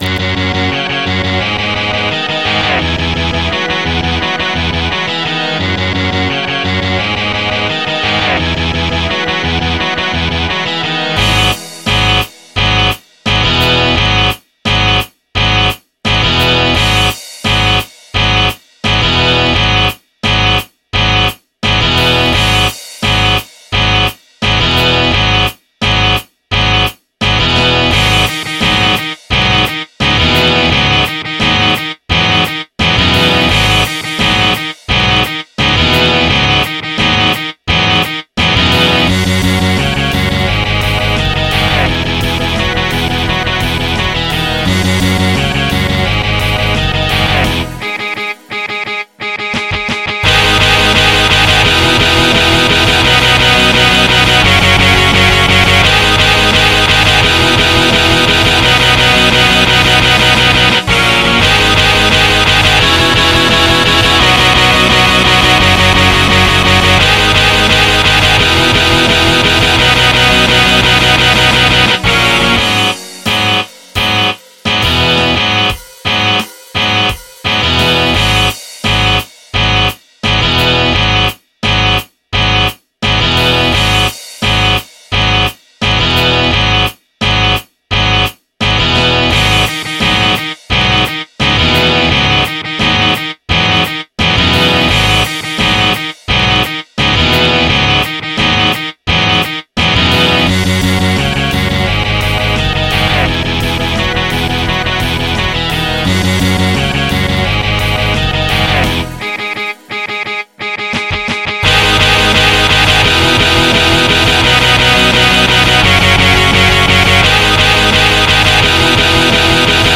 MP3 (Converted)
My instrumental midi